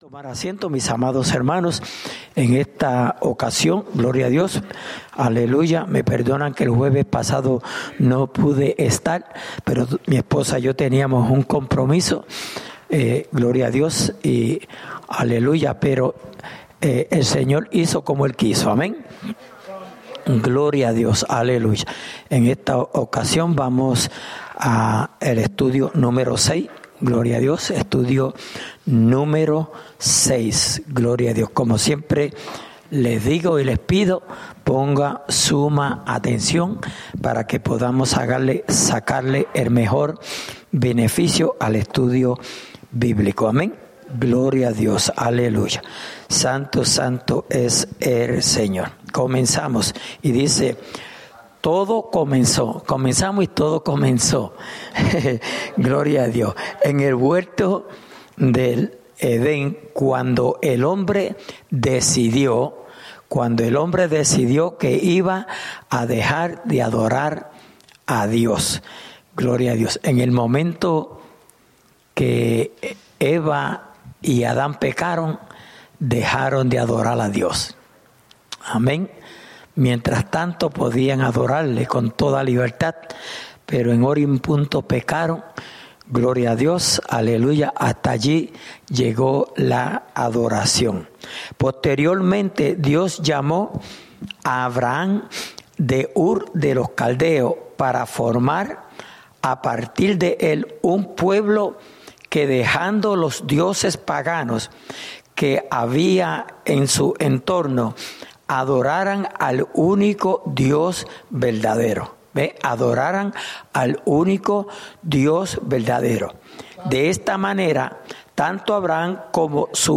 Estudio Bíblico: La adoración ( Parte 6)